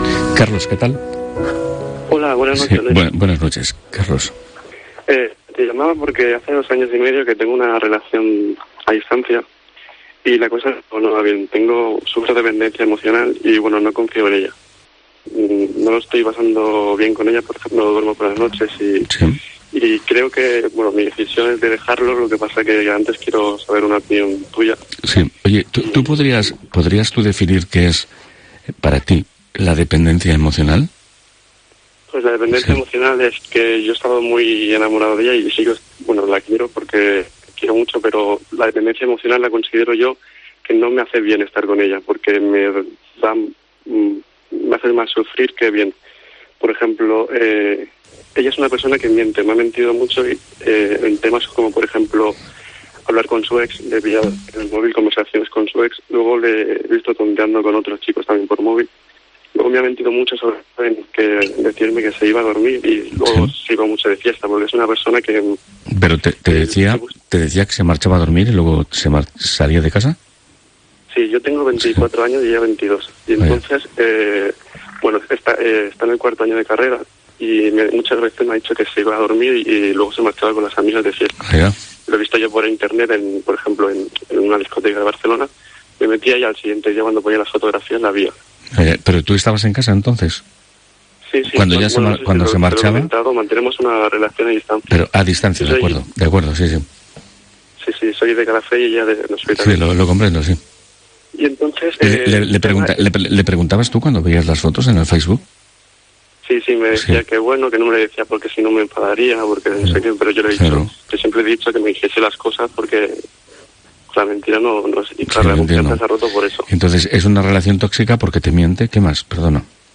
Radio de madrugada